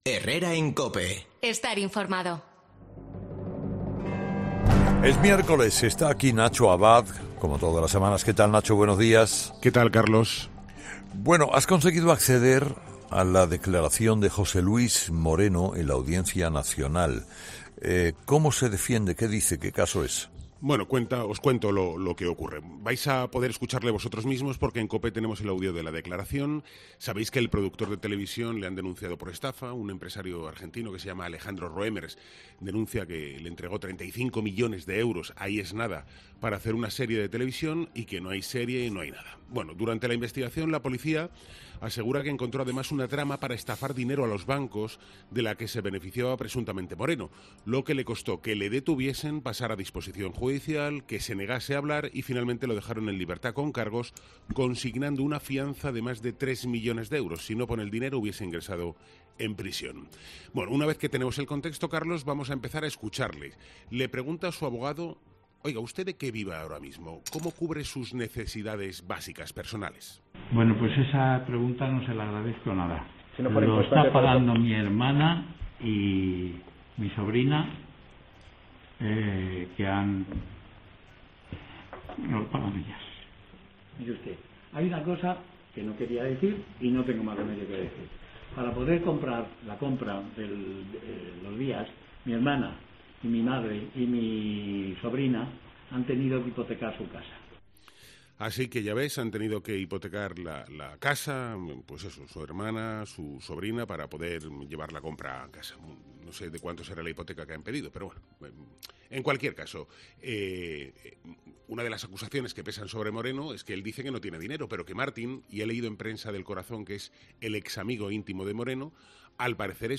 Los audios inéditos de la declaración judicial de José Luis Moreno: "Esto me ha arruinado la vida"